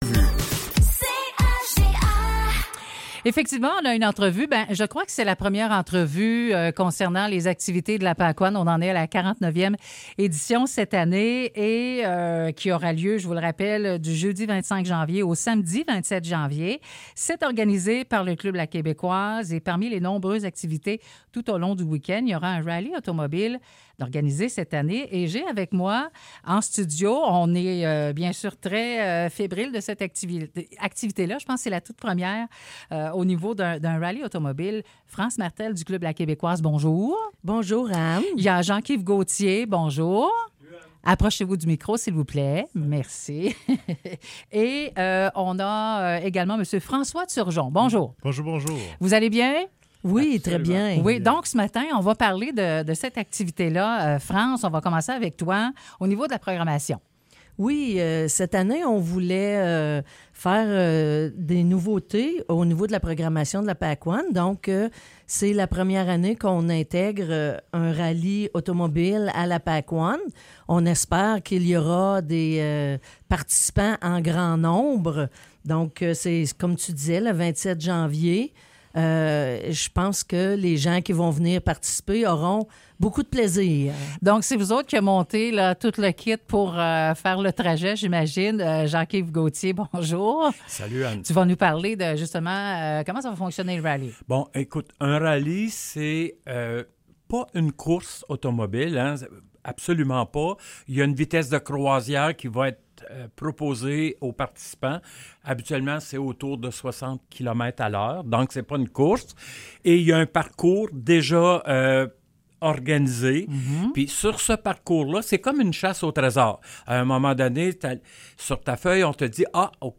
Entrevue dans le cadre de la 49ème Pakwaun